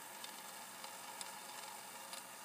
motor rewind loaded.aiff